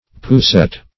Poussette \Pous*sette"\ (p[=oo]*s[e^]t"), n. [F., pushpin, fr.